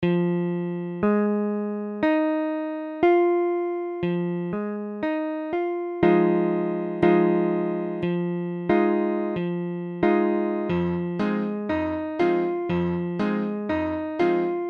Fm7 : accord de Fa mineur septi�me Mesure : 4/4
Tempo : 1/4=60
I_Fm7.mp3